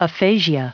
added pronounciation and merriam webster audio
226_aphasia.ogg